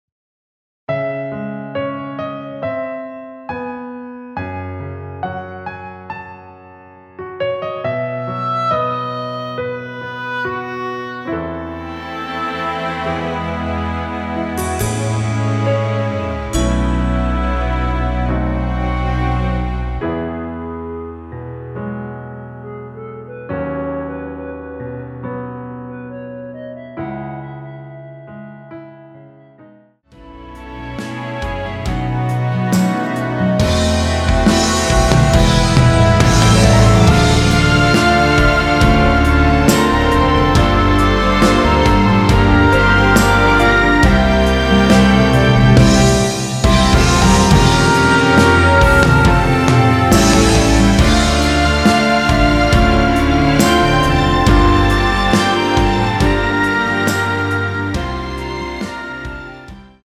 원키에서(-1)내린 멜로디 포함된 MR입니다.
앞부분30초, 뒷부분30초씩 편집해서 올려 드리고 있습니다.